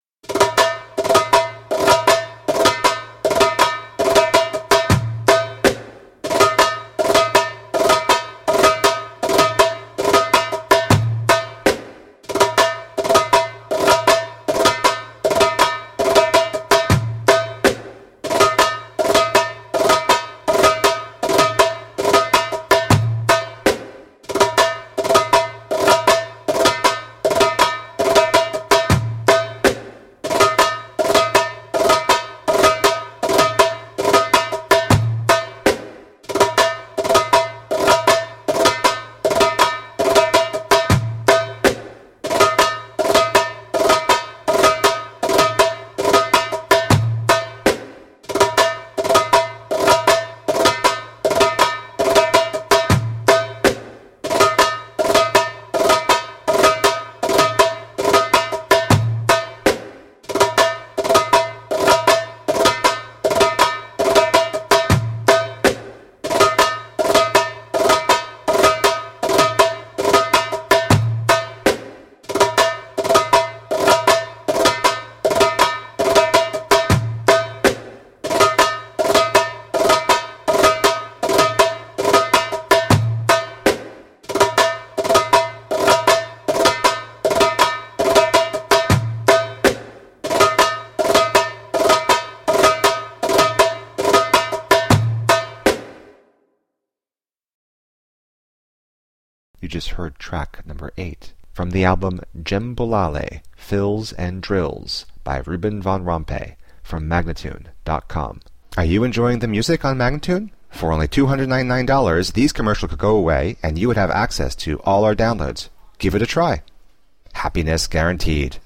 Passionate eastern percussion.